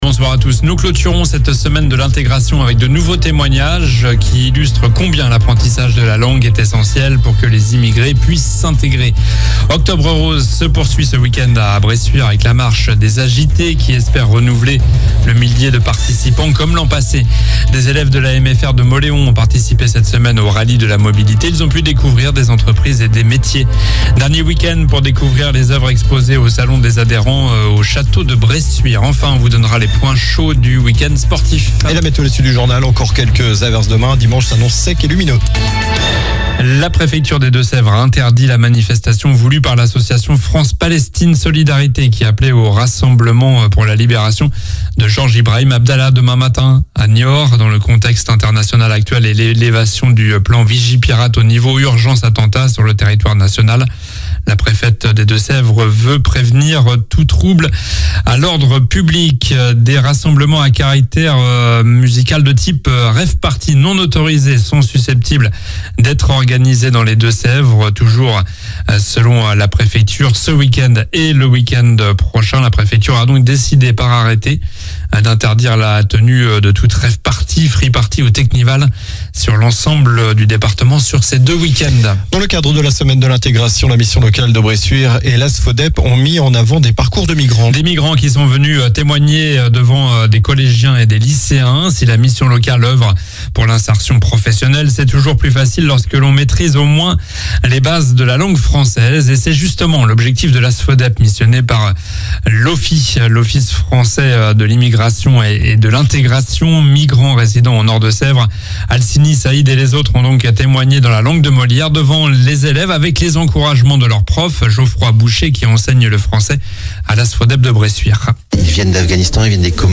Journal du vendredi 20 octobre (soir)